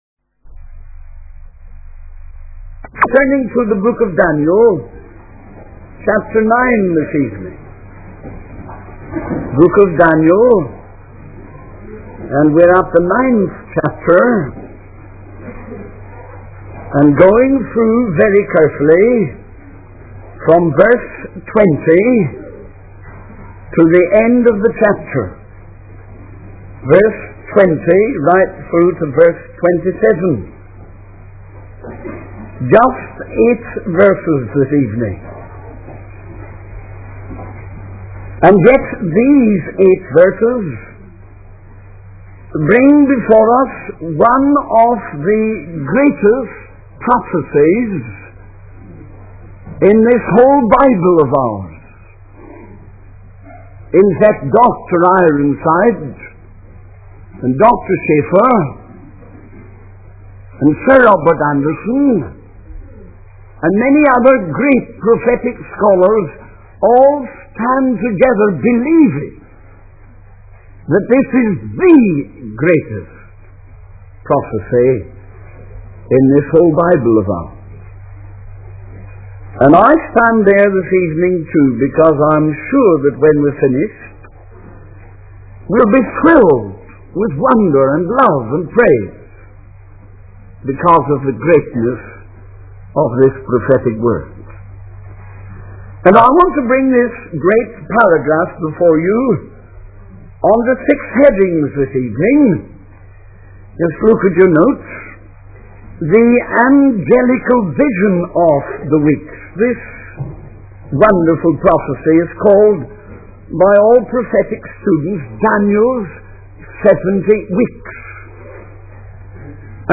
The sermon concludes with a call to recognize the urgency of the times and the impending fulfillment of God's prophetic pl